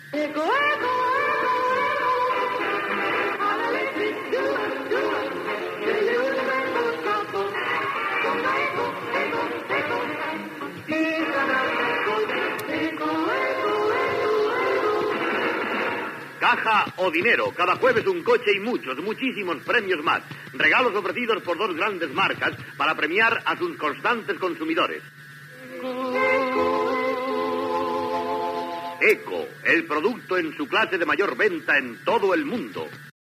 Publicitat, identificació del programa, publicitat.